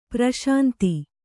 ♪ praś`nti